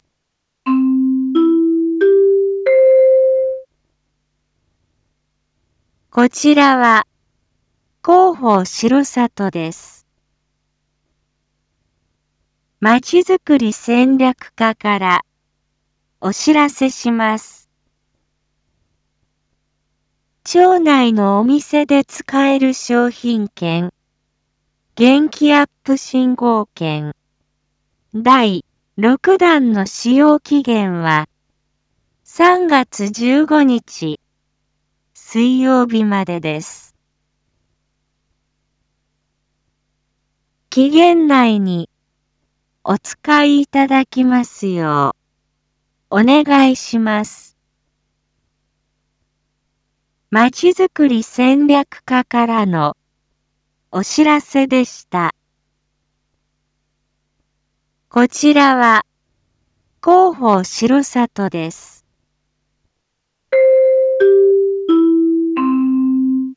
一般放送情報
Back Home 一般放送情報 音声放送 再生 一般放送情報 登録日時：2023-03-04 19:01:08 タイトル：R5.3.4 19時放送分 インフォメーション：こちらは、広報しろさとです。